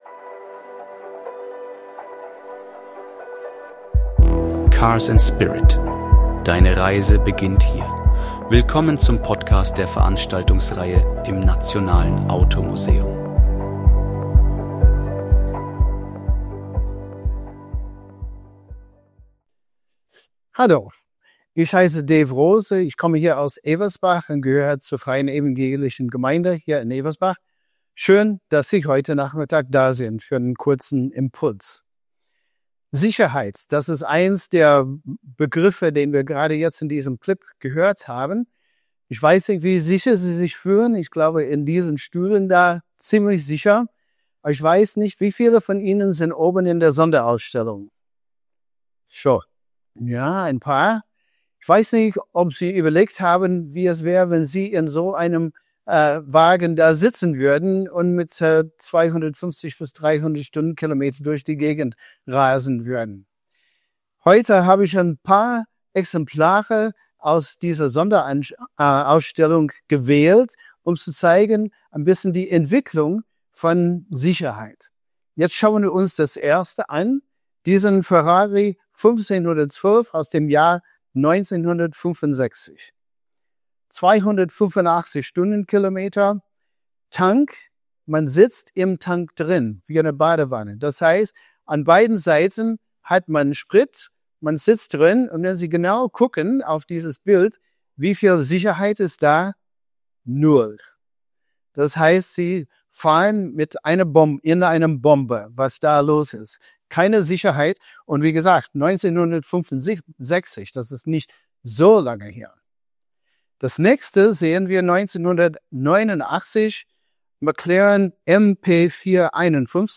Beschreibung vor 2 Monaten Wie sicher ist Motorsport wirklich? In diesem eindrucksvollen Impuls im Nationalen Automuseum